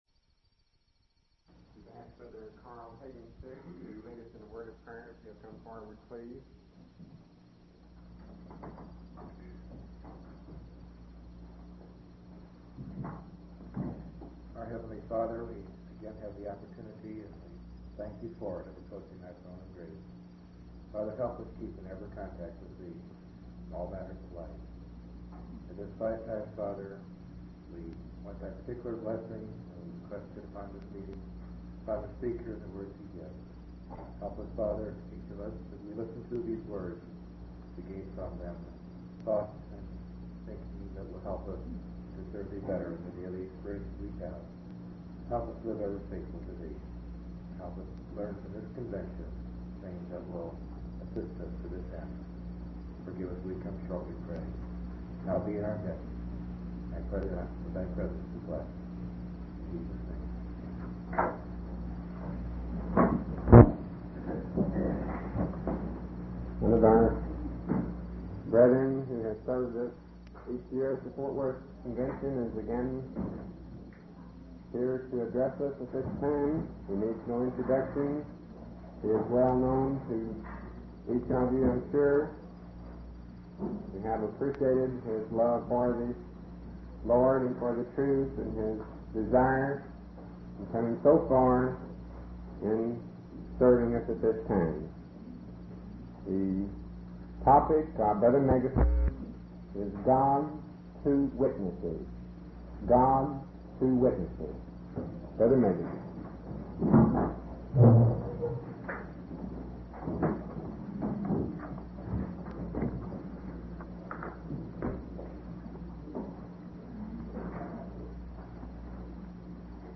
From Type: "Discourse"
Given at Ft. Worth, TX - Convention